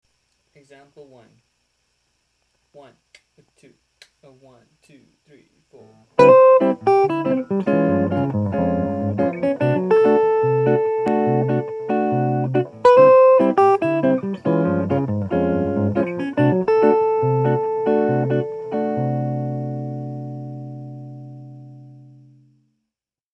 For ease of understanding, I've written all the examples as Major II-V-I progressions in the key of C Major.
(Listen) - In this line, I used the C Major and Db arpeggio in a descending and then ascending direction followed by the arpeggios as linear open-voiced triads.